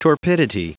Prononciation du mot torpidity en anglais (fichier audio)
Prononciation du mot : torpidity